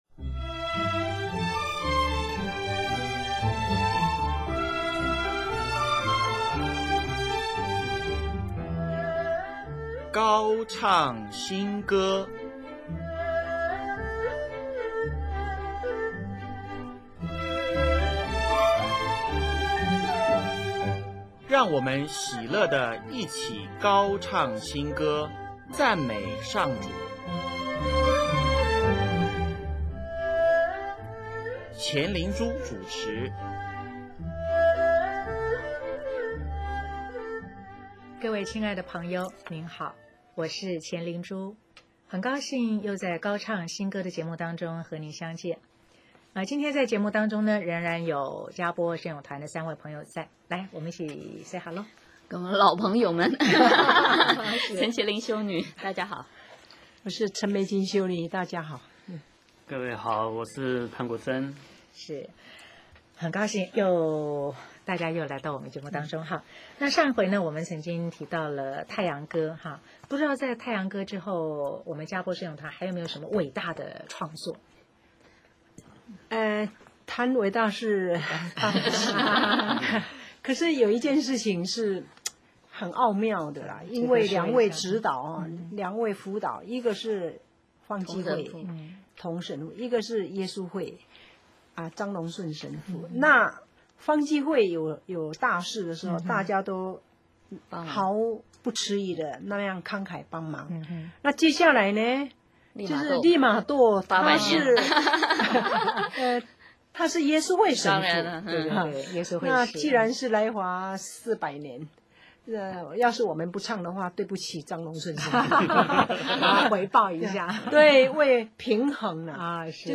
【高唱新歌】10|专访“佳播圣咏团”(六)：醒來吧，我心